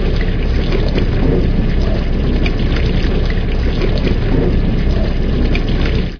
techage_reboiler.ogg